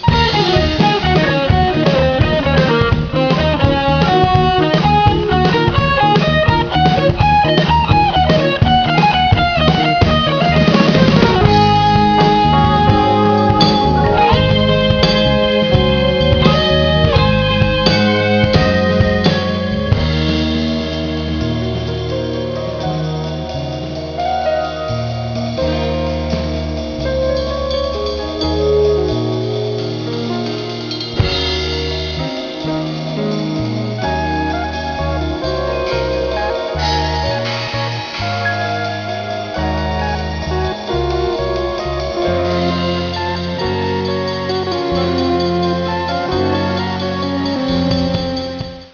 guitars
drums
vocals, bass and keyboards
recorded 8 track digital adat sep'95 through oct'96